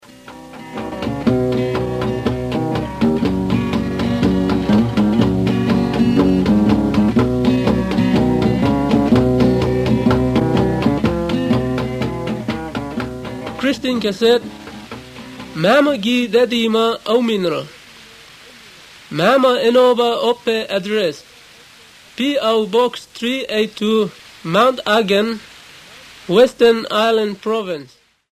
These are recorded by mother-tongue speakers